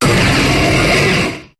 Cri de Kyurem Noir dans Pokémon HOME.